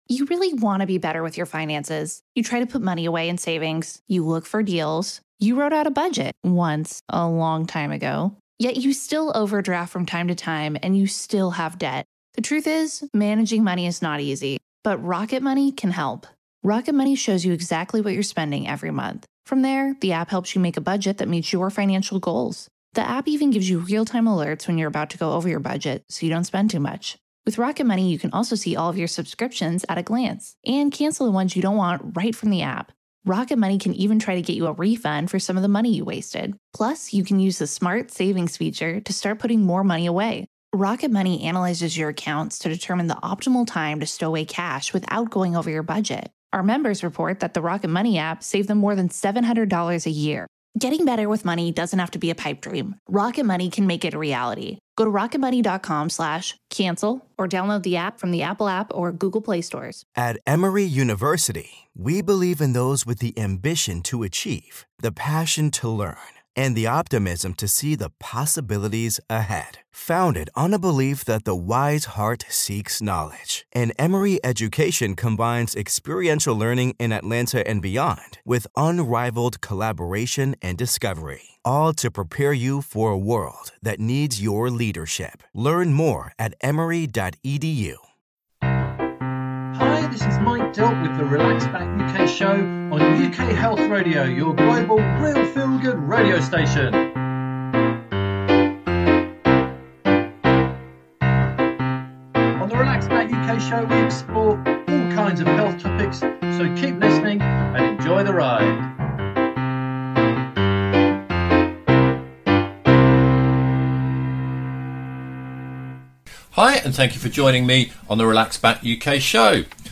Hear from a recovered alcoholic who has written a book to help others.